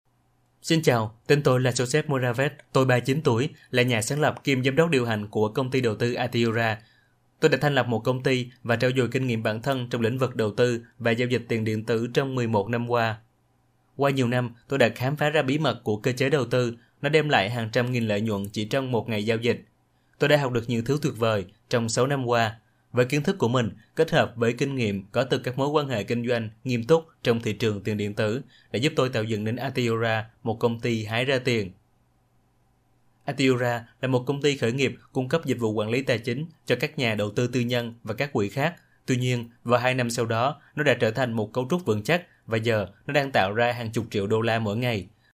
当前位置：首页> 样音试听 >优选合集 >外语配音合集 >越南语配音